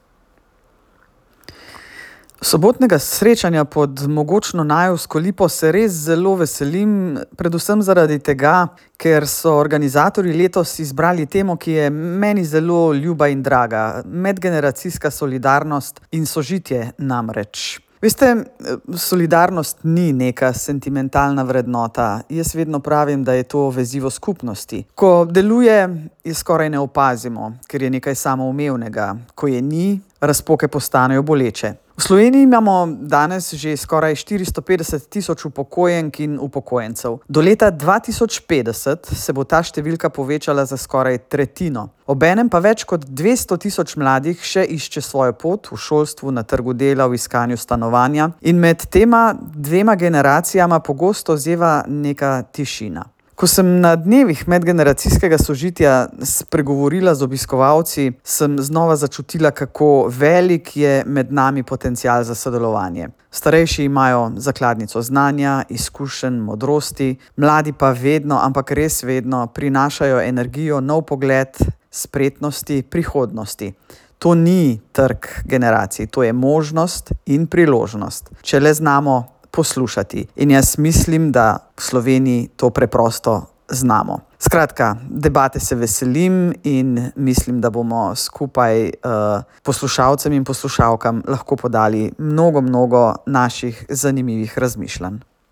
Slavnostna govornica bo predsednica republike Nataša Pirc Musar, ki je za Koroški radio pred srečanjem povedala:
izjava predsednice republike pred Najevsko lipo.mp3